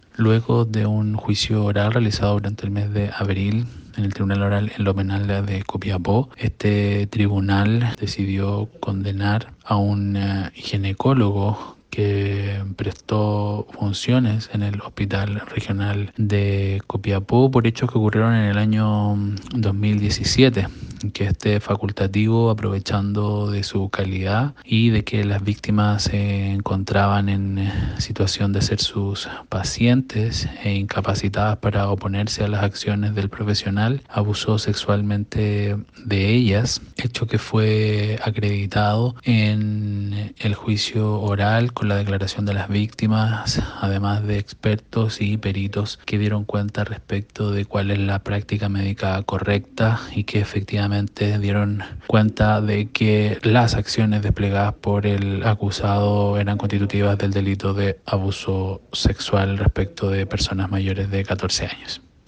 AUDIO-FISCAL-GUILLERMO-ZARATE.mp3